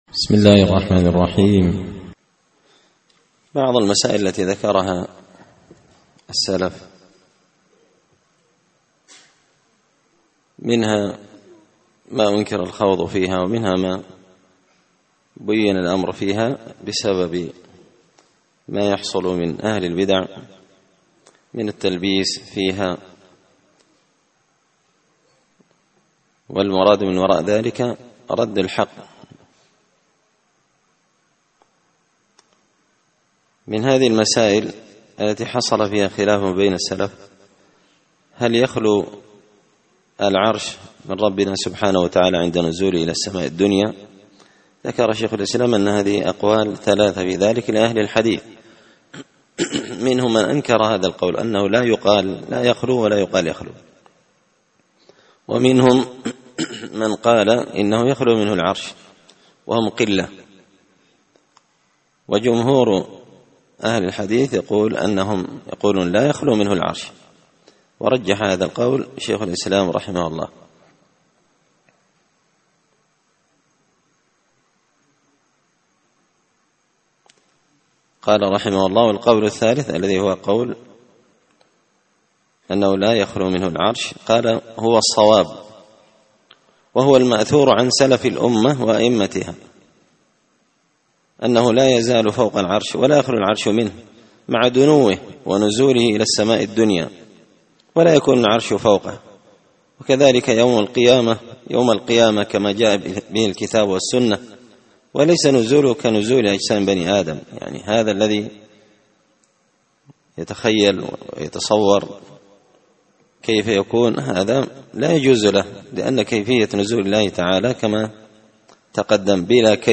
شرح الشيخ